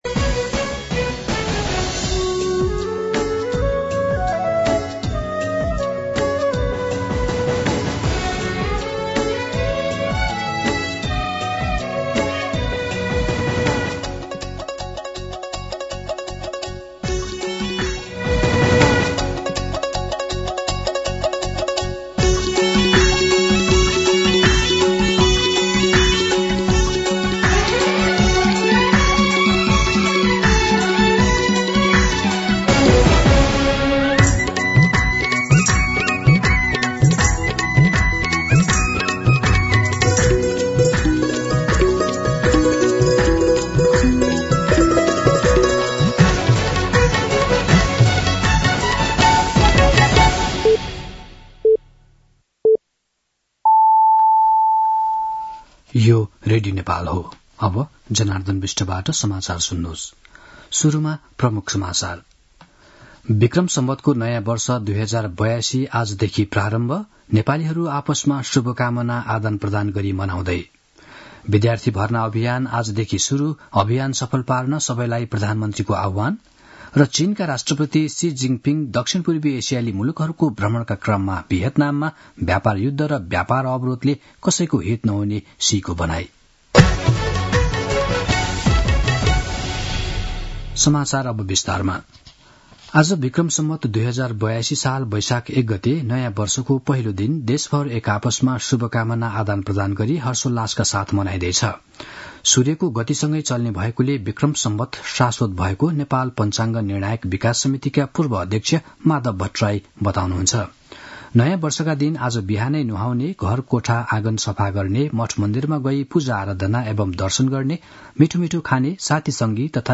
दिउँसो ३ बजेको नेपाली समाचार : १ वैशाख , २०८२
3-pm-news-1.mp3